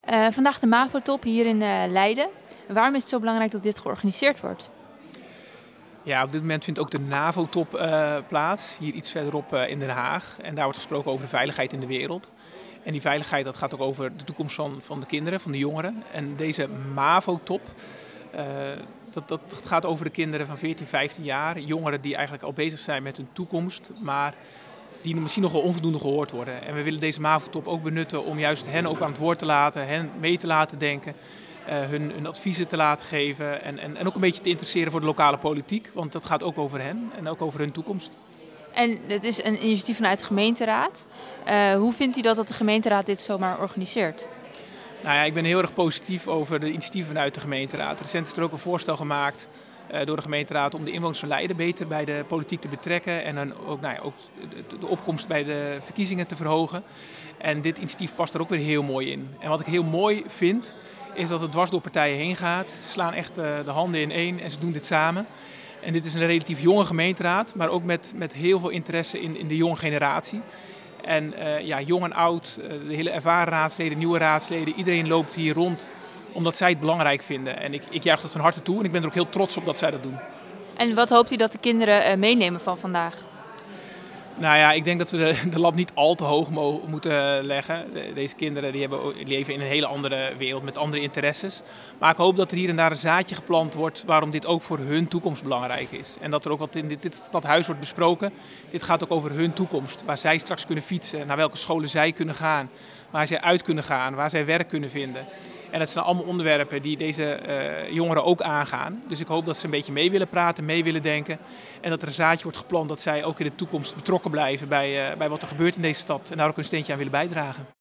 Burgemeester Peter Heijkoop over de MAVO-top